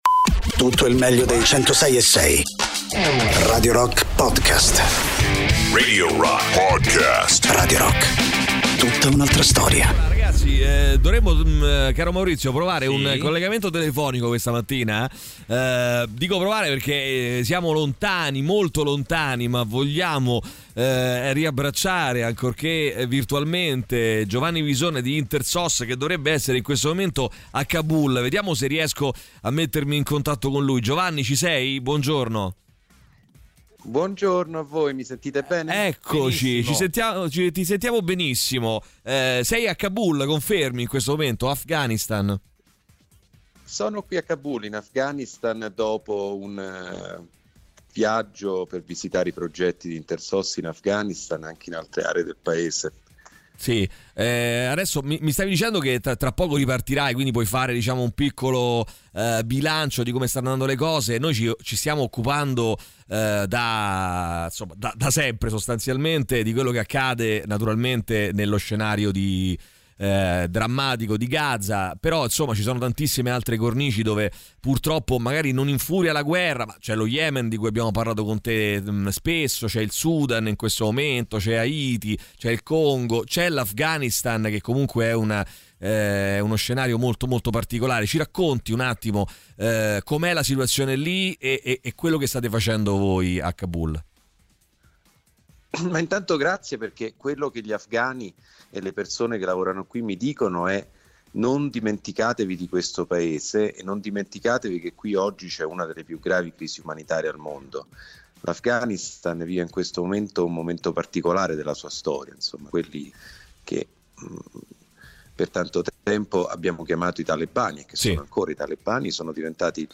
Interviste
ospite telefonico